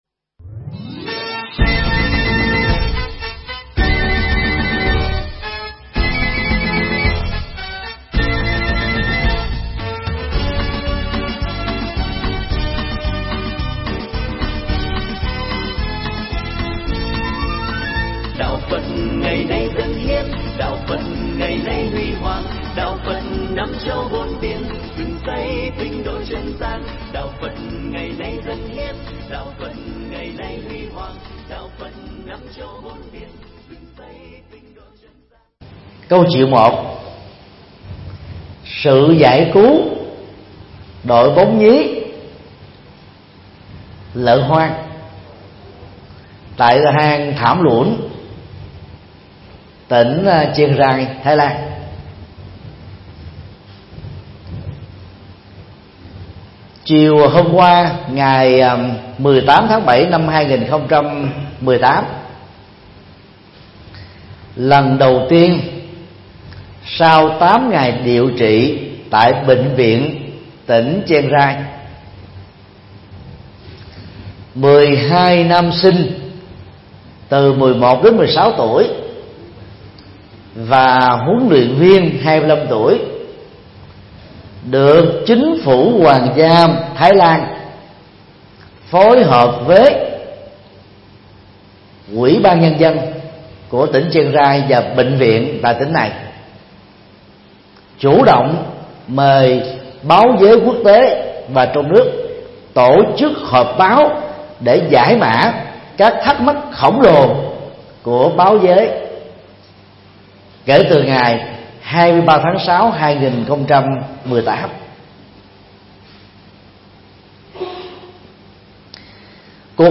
Mp3 Pháp Thoại Những Câu Chuyện Về Tâm Từ Bi Dưới Góc Nhìn Phật Giáo – Thượng Tọa Thích Nhật Từ giảng tại chùa Long Thành (Vĩnh Long), ngày 19 tháng 7 năm 2018